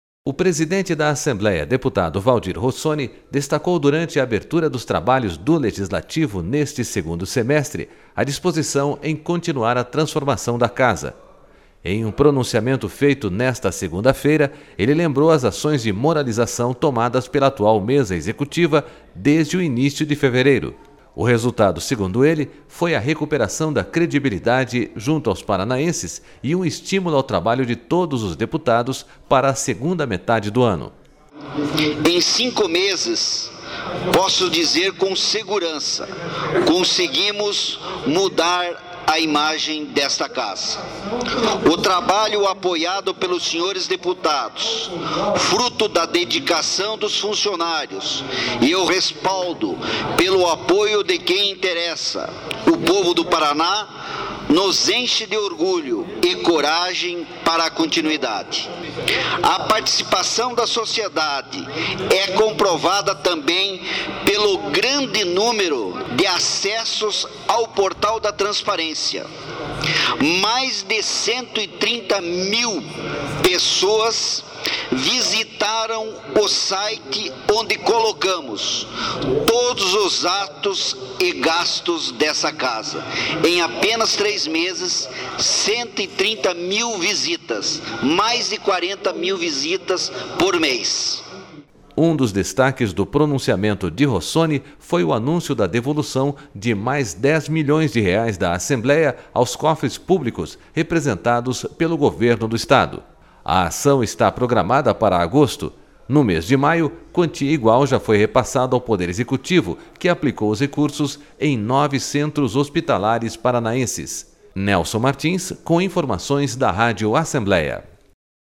O presidente da Assembleia, deputado Valdir Rossoni, destacou durante a abertura dos trabalhos do Legislativo neste segundo semestre a disposição em continuar a transformação da Casa.//Em um pronunciamento feito nesta segunda-feira, ele lembrou as ações de moralização tomadas pela atual Mesa Executi...